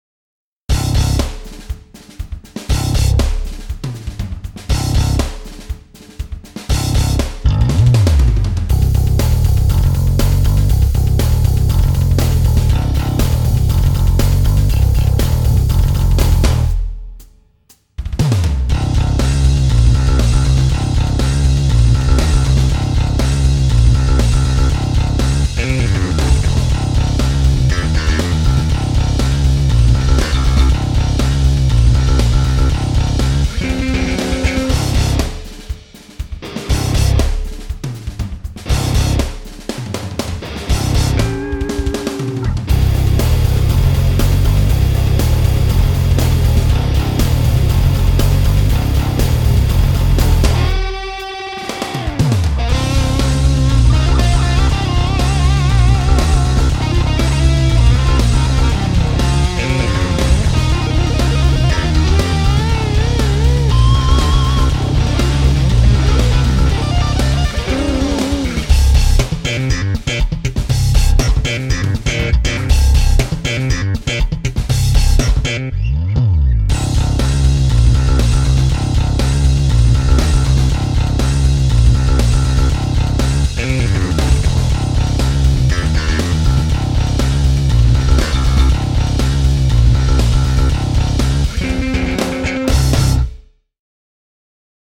These were all made using ManyBass sounds: there has been no external processing.
ManyBass - Rockin' it out!